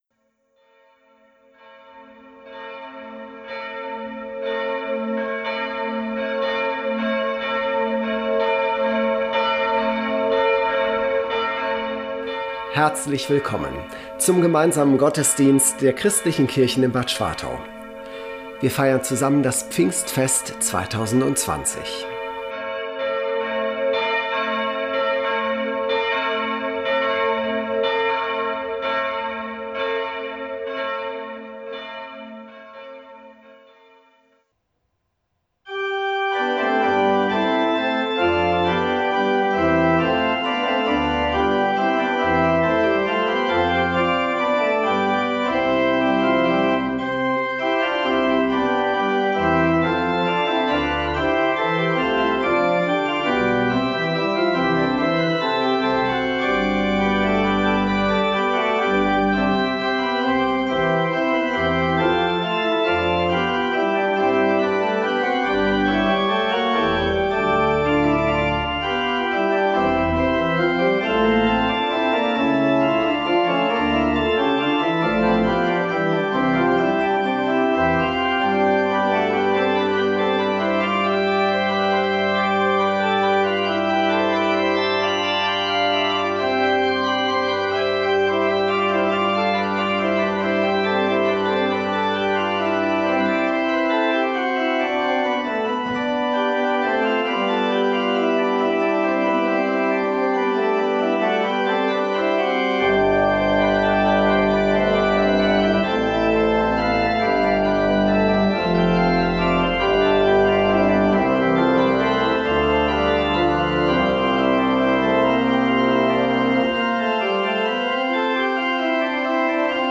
Ökumenisches Pfingstfest als Audiogottesdienst
Der traditionelle ökumenische Gottesdienst am Pfingstmontag in Bad Schwartau wurde vom Kurpark ins Internet verlegt. Acht Gemeinden waren an der Produktion eines Audiogottesdienstes beteiligt.